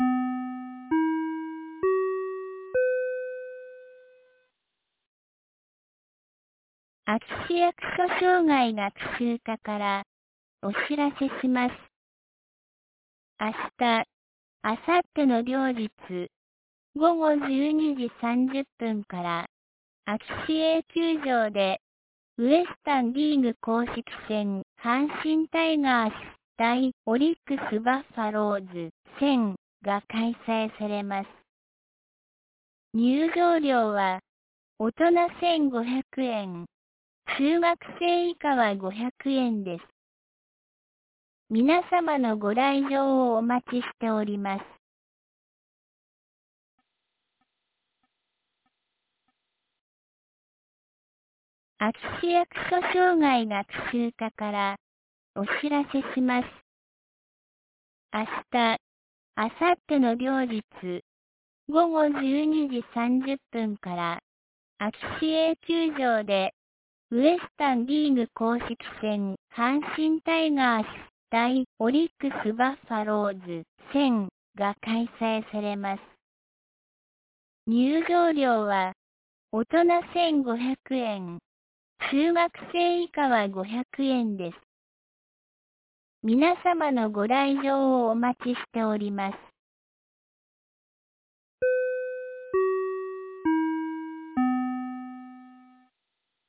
2024年09月06日 16時31分に、安芸市より全地区へ放送がありました。